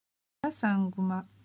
Ecoutez comment on dit certains mots de l’histoire en japonais: